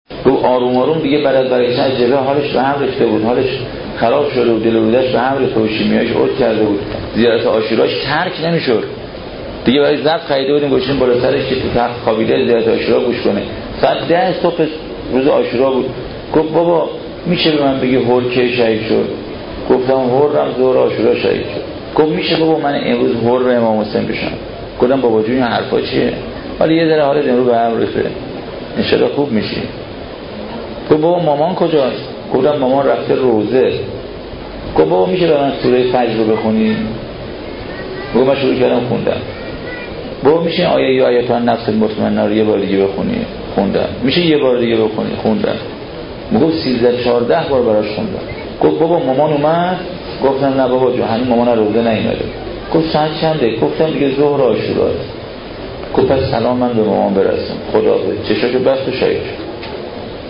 دسته بندی صوت‌ها بی سیم بیانات بزرگان پادکست روایتگری سرود شرح عملیات صوت شهدا کتاب گویا مداحی موسیقی موسیقی فیلم وصیت نامه شهدا گلف چند رسانه‌ای صوت روایتگری میشه من حر عاشورا بشم؟